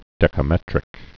(dĕkə-mĕtrĭk)